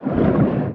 Sfx_creature_pinnacarid_swim_fast_05.ogg